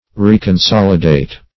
Search Result for " reconsolidate" : The Collaborative International Dictionary of English v.0.48: Reconsolidate \Re`con*sol"i*date\ (r?`k?n*s?l"?*d?t), v. t. To consolidate anew or again.
reconsolidate.mp3